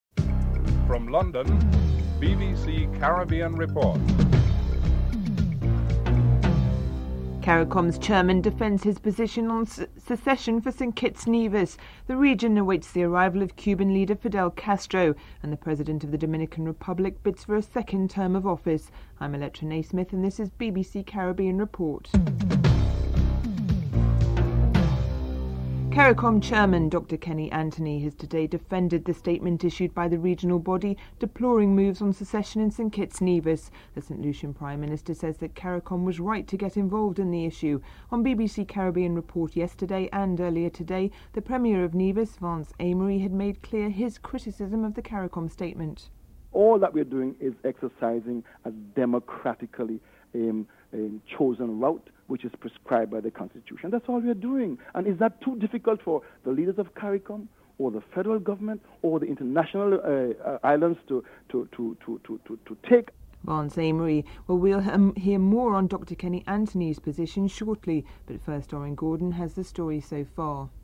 1. Headlines (00:00-00:25)
Deputy Prime Minister Seymour Mullings is interviewed (09:34-10:26)